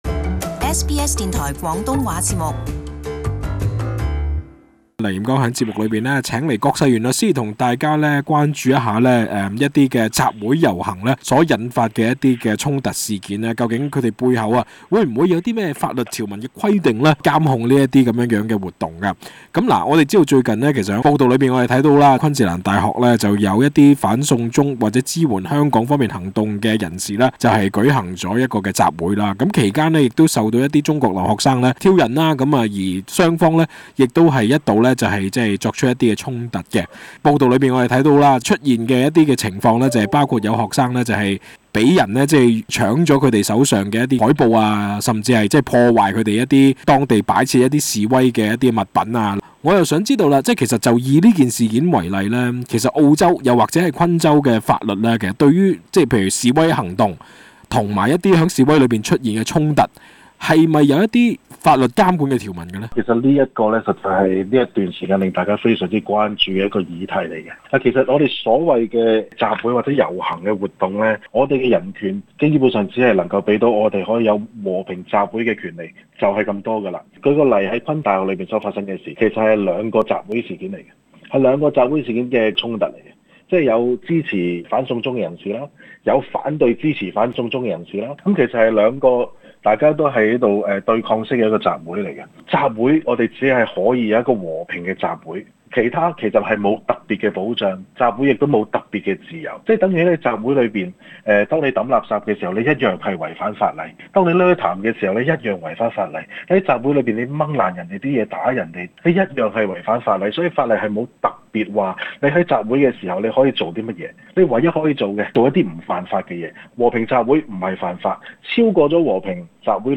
【时事专访】集会自由外 澳洲法律对示威者是否有特殊保障？